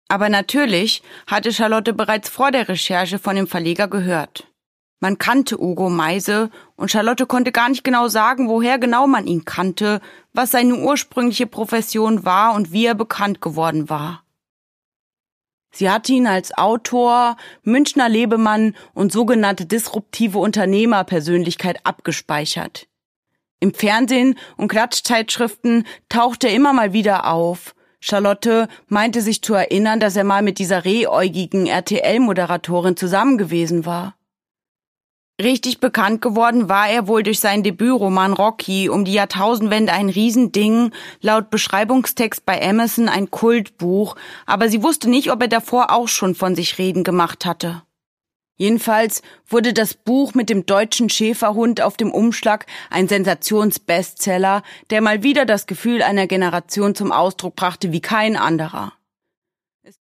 Caroline Wahl: Die Assistentin (Ungekürzte Lesung)
Produkttyp: Hörbuch-Download
Gelesen von: Caroline Wahl